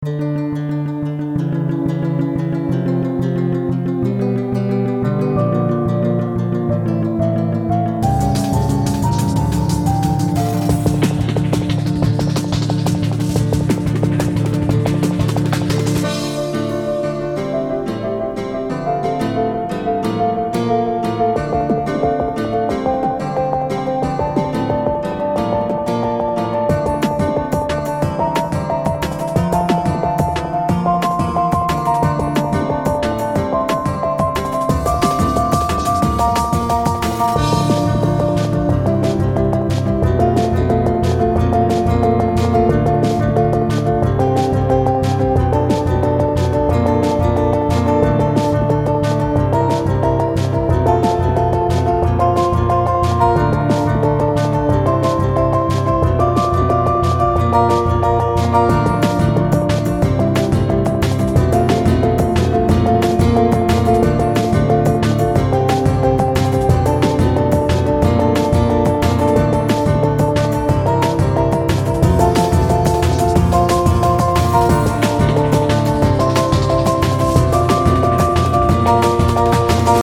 Key Instruments: Piano, Synth Guitar, Drums